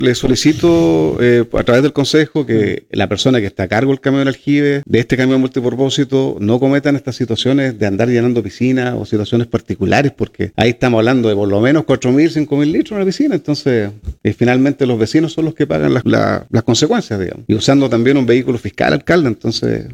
El concejal Juan Luis Añazco, debido a reiteradas denuncias por parte de los vecinos, expuso en el pleno del concejo municipal de Puerto Octay que un camión multipropósito aljibe se encontraba realizando llenado de piscinas particulares.